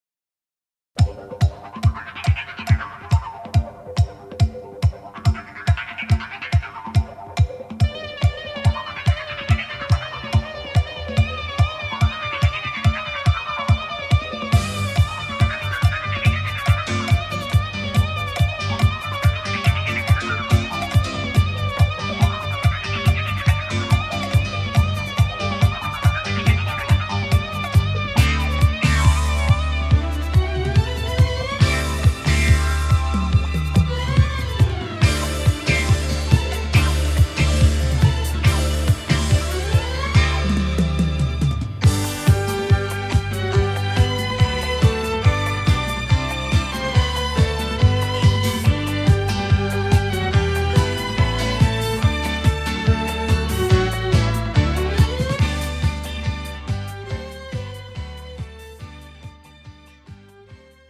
MR 고음질 반주